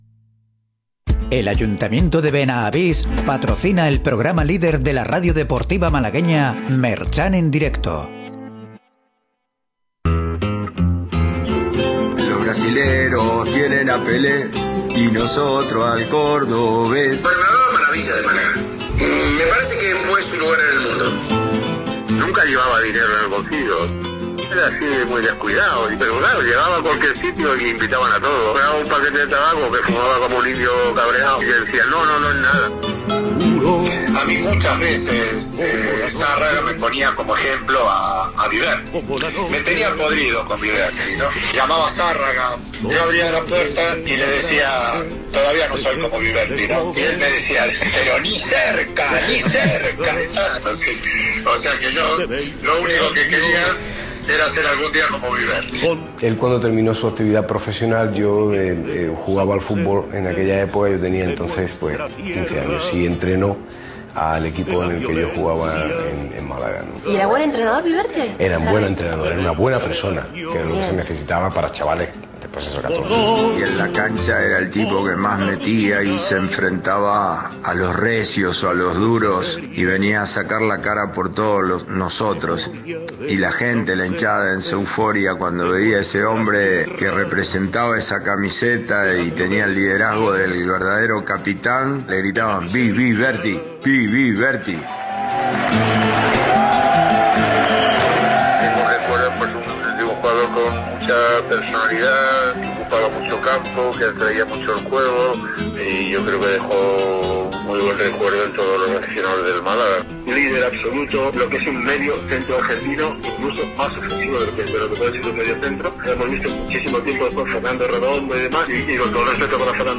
Un monográfico especial dedicado al ‘Zapatones’ y que contó con invitados de lujo.